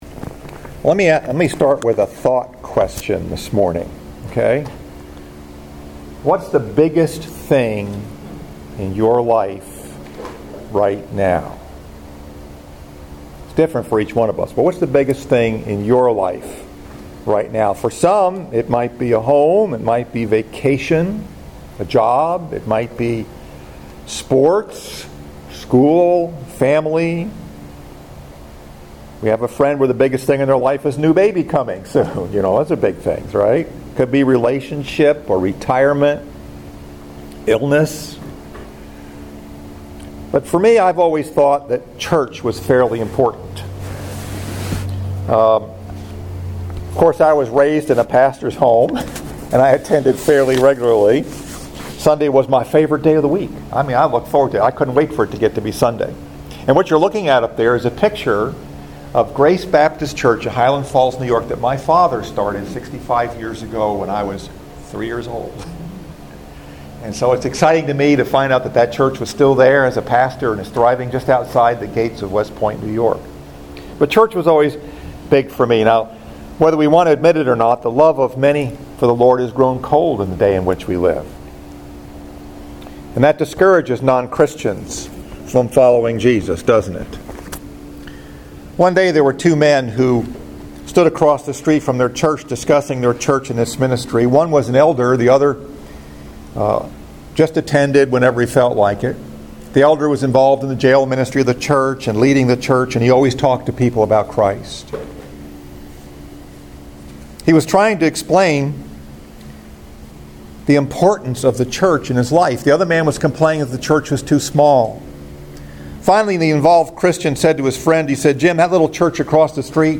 Message: “The Best Kind of Church” -Part 2 Scripture: 2 Corinthians 8, 9